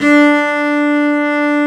Index of /90_sSampleCDs/Roland - Brass, Strings, Hits and Combos/STR_Vc Arco Solo/STR_Vc Arc mf nv
STR CELLO 0D.wav